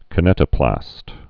(kə-nĕtə-plăst, -nētə-, kī-)